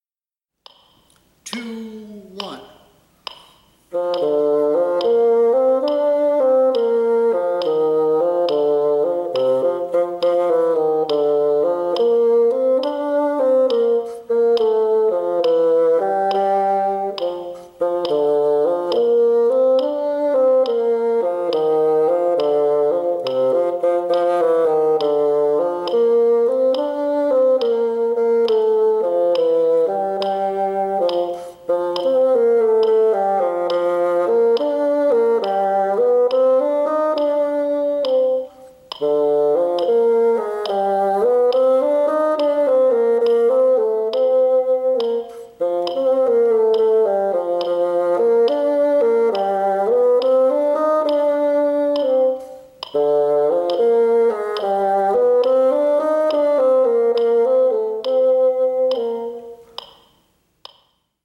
P. 24, Duet in Bb Major, Tempo 69, Bassoon 1